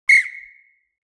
جلوه های صوتی
دانلود صدای اعلان خطر 11 از ساعد نیوز با لینک مستقیم و کیفیت بالا